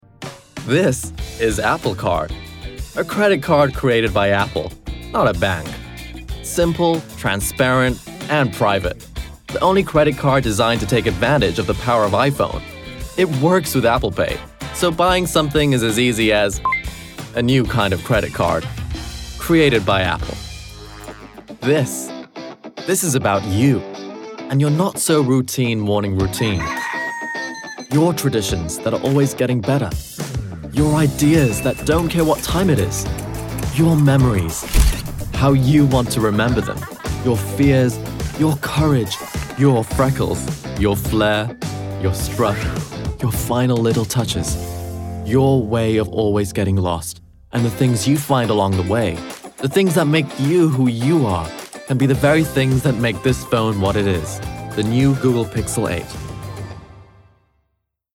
Commercial Reel (American)
Commercial, Bright, Upbeat, Conversational